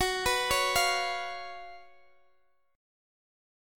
Listen to F#M7sus4 strummed